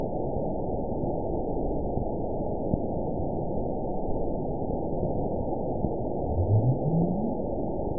event 920110 date 02/23/24 time 01:45:05 GMT (1 year, 9 months ago) score 9.65 location TSS-AB01 detected by nrw target species NRW annotations +NRW Spectrogram: Frequency (kHz) vs. Time (s) audio not available .wav